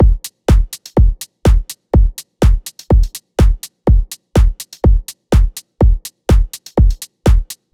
Index of /99Sounds Music Loops/Drum Loops/Dance